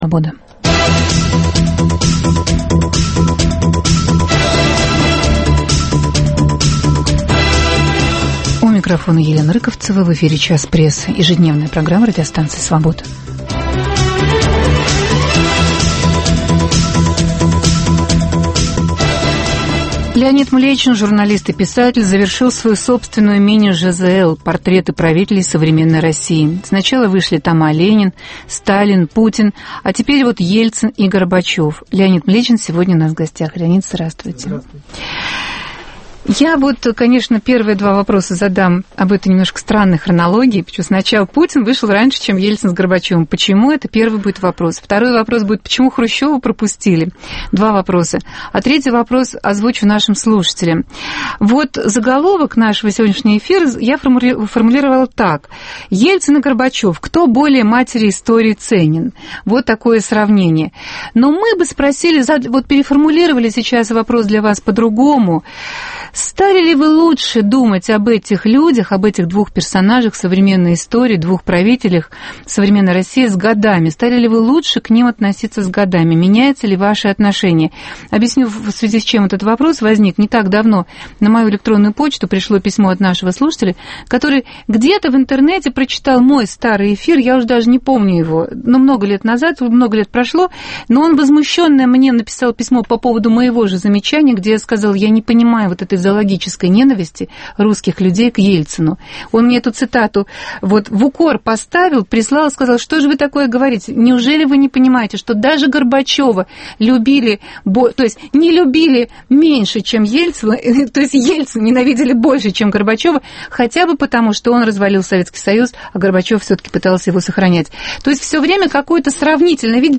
В прямом эфире Леонид Млечин расскажет об этой своей мини-ЖЗЛ.